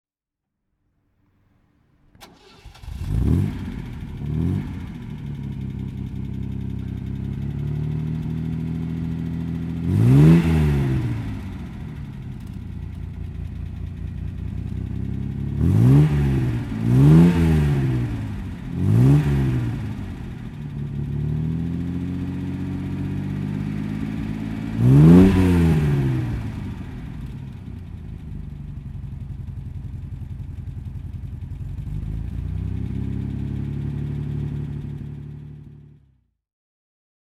MGC GT (1969) - Starten und Leerlauf